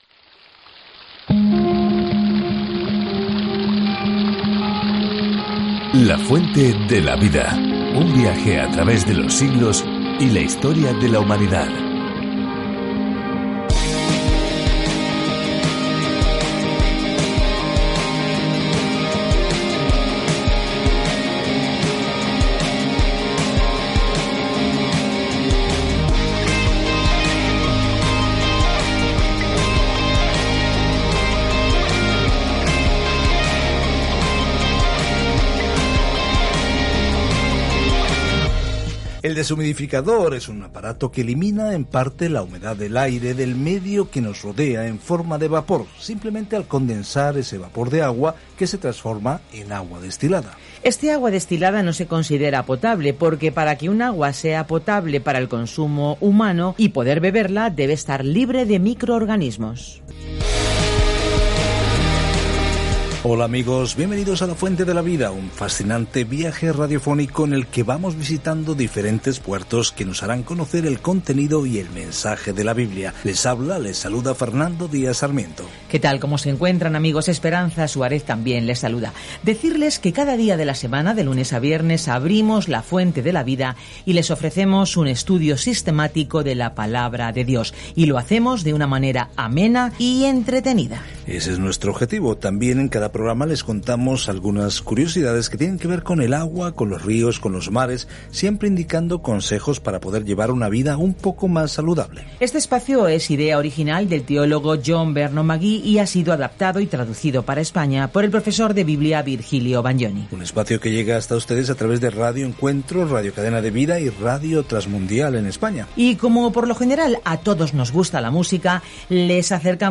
Viaja diariamente a través de Isaías mientras escuchas el estudio en audio y lees versículos seleccionados de la palabra de Dios.